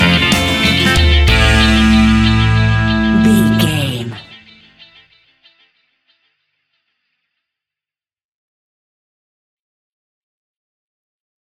Aeolian/Minor
dub
laid back
chilled
off beat
drums
skank guitar
hammond organ
percussion
horns